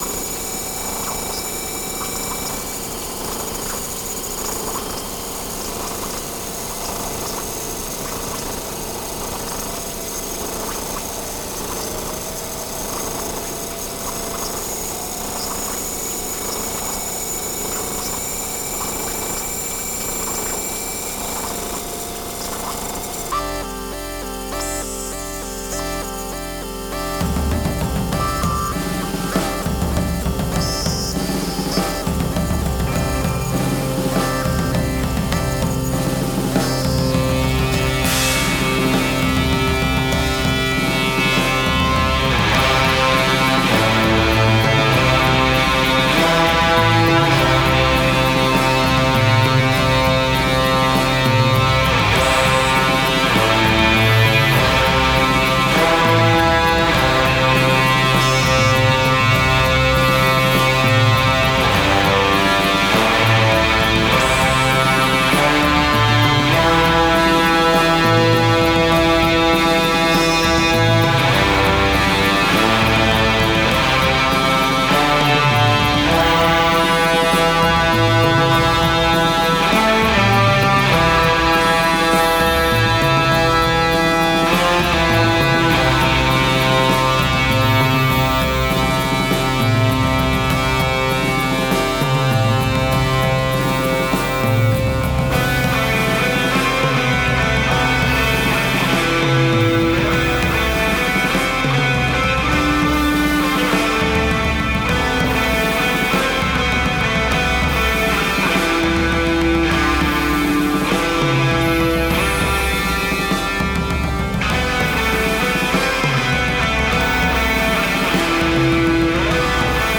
Genre: Post Rock.